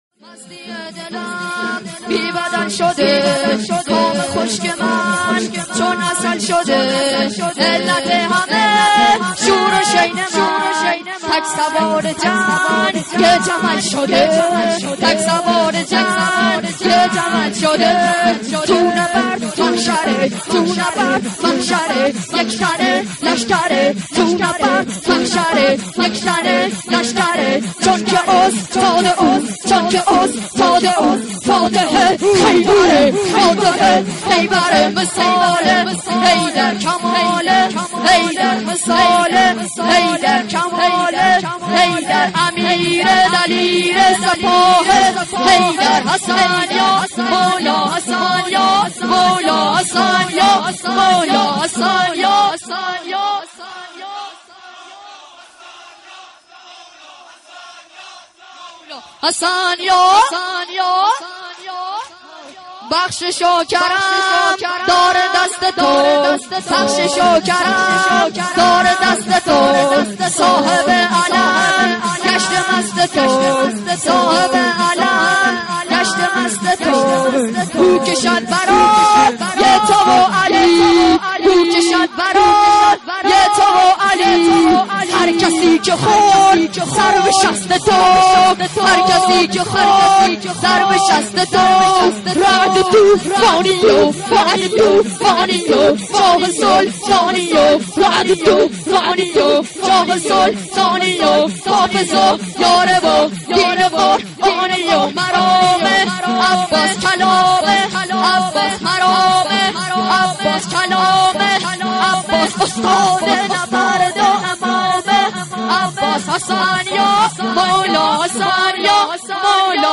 مراسم هفتگی مکتب الزهرا(سلام الله علیها):
شور